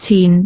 speech
syllable
pronunciation